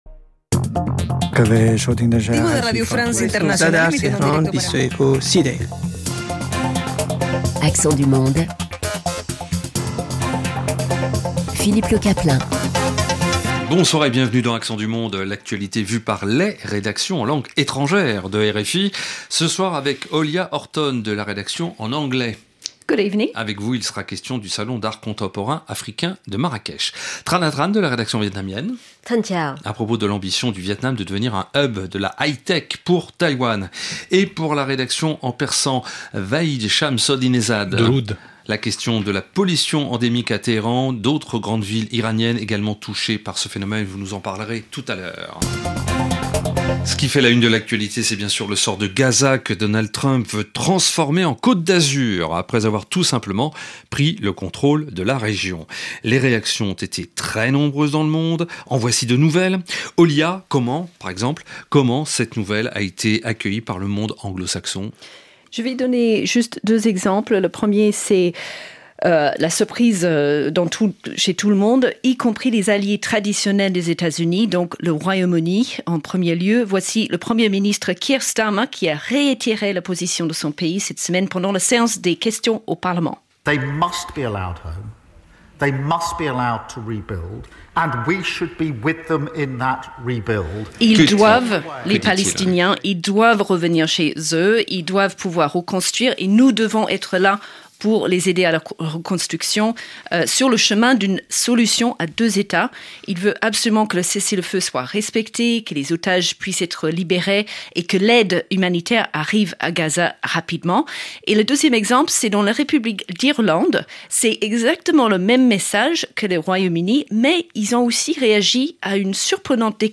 Le vendredi soir, dans Accents du monde, les journalistes des rédactions en langues étrangères croisent leurs regards, en français, sur l’actualité internationale et partagent les événements et les faits de société de leur région.